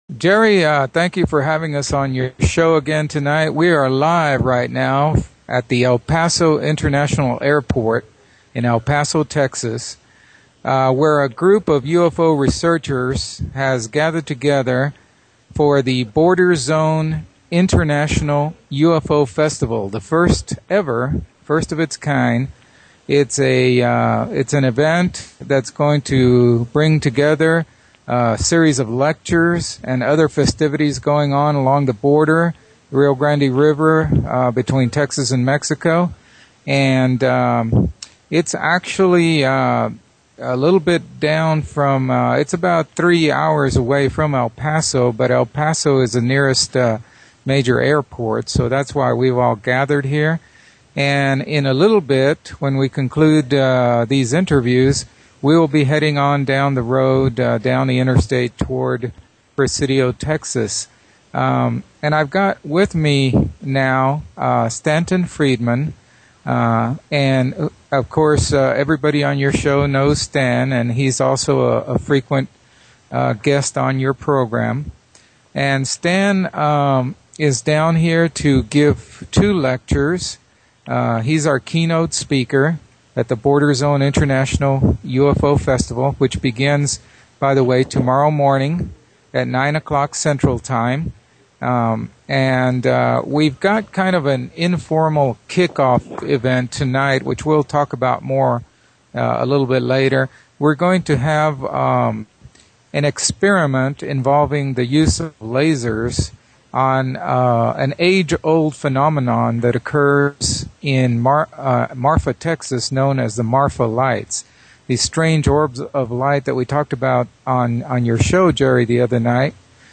MP3 Running time: 54 minutes, 38 seconds Windows Media Version Part 1 - Windows Media Version Part 2 (This interview originally broadcast October 15, 2012 on Inception Radio Network)